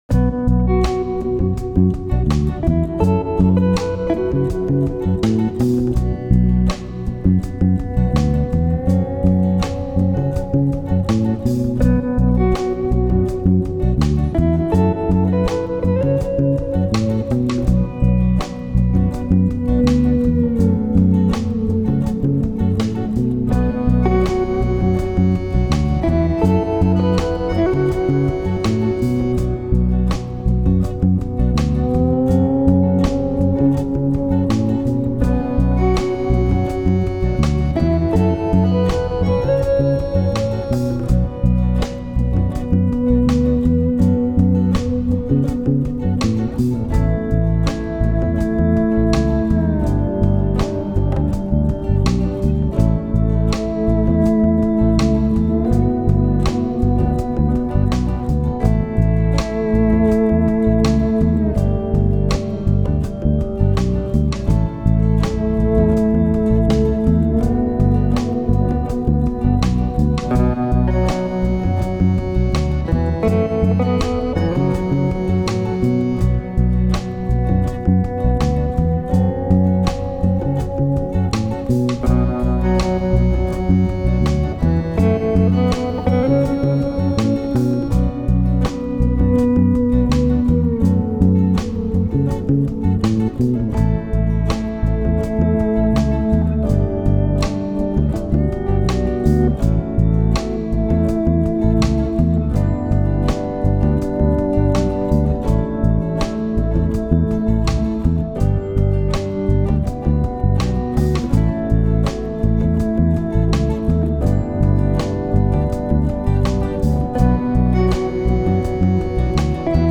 Dance e Eletrônica